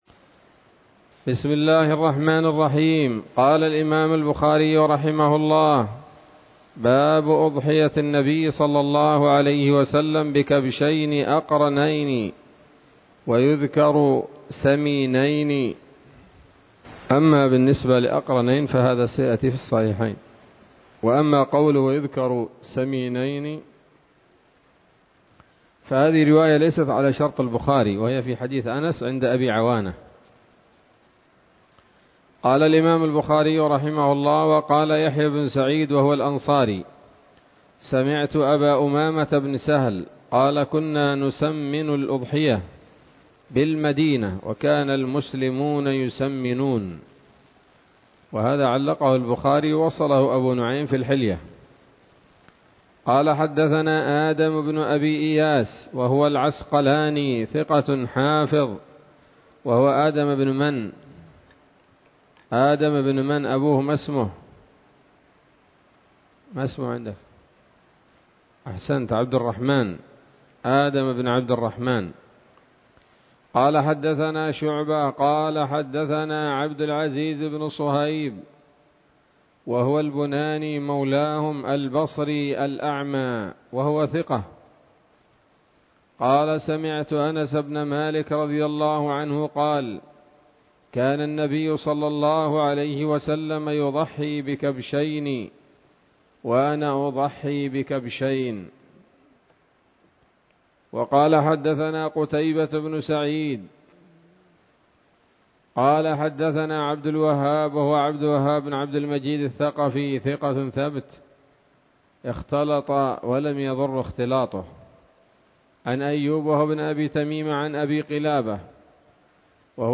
الدرس السابع من كتاب الأضاحي من صحيح الإمام البخاري